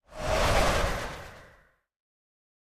1.21.5 / assets / minecraft / sounds / mob / breeze / idle1.ogg